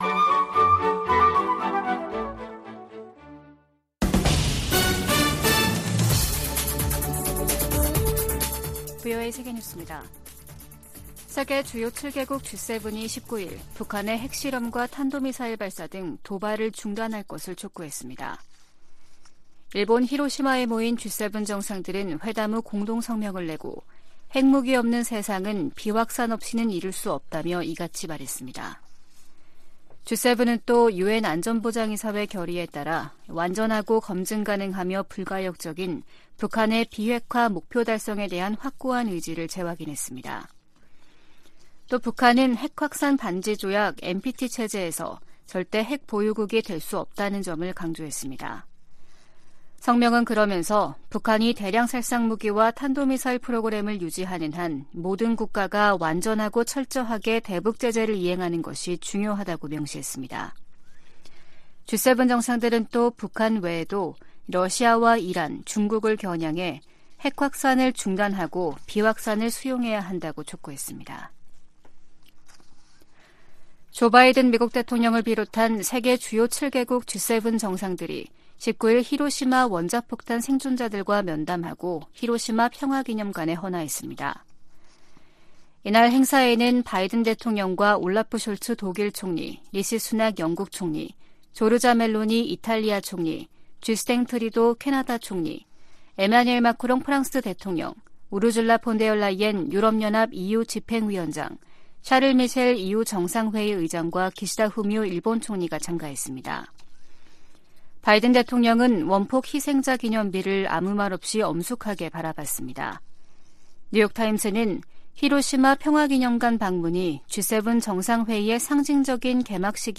VOA 한국어 아침 뉴스 프로그램 '워싱턴 뉴스 광장' 2023년 5월 20일 방송입니다. 미국과 일본 정상이 히로시마에서 회담하고 북한의 핵과 미사일 문제 등 국제 현안을 논의했습니다. 윤석열 한국 대통령이 19일 일본 히로시마에 도착해 주요7개국(G7) 정상회의 참가 일정을 시작했습니다. 북한이 동창리 서해발사장에 새로짓고 있는 발사대에서 고체연료 로켓을 시험발사할 가능성이 높다고 미국 전문가가 분석했습니다.